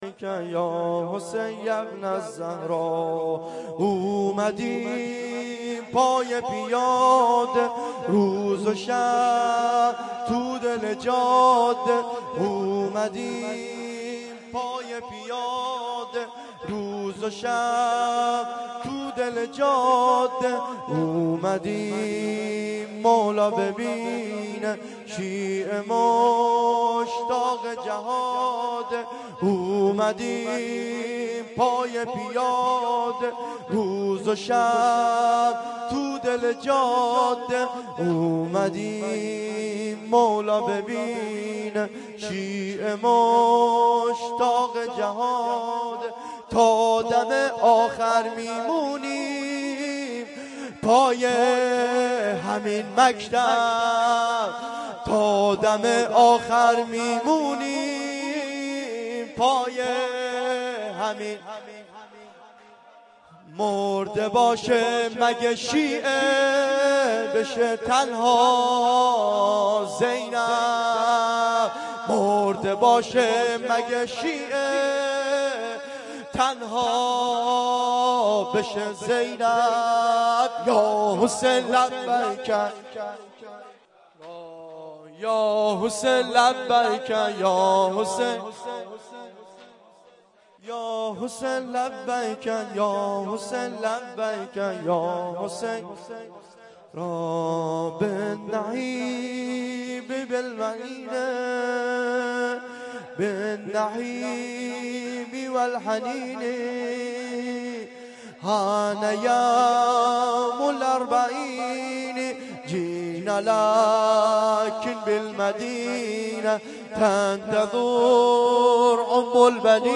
دو ضرب